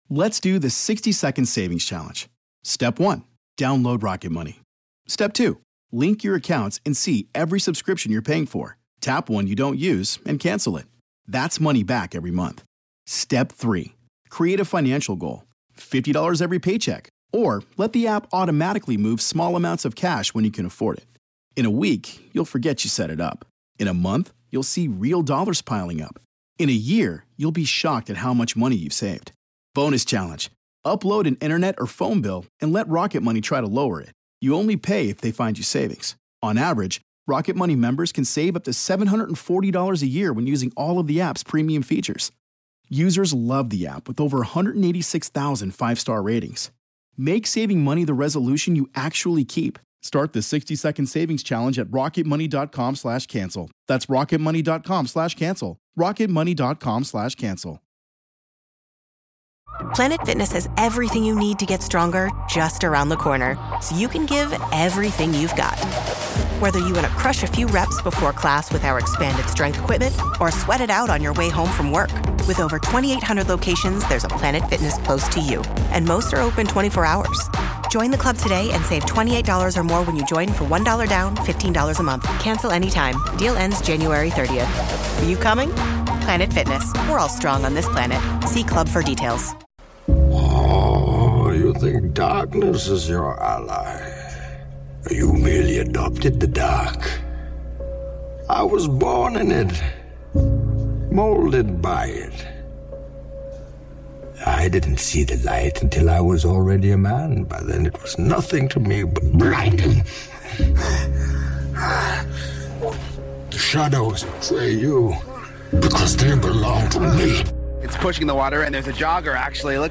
Prepare to be inspired by the words of David Goggins, one of the most powerful voices in motivation. With a focus on resilience, determination, and the relentless pursuit of greatness, this compilation will challenge you to your core.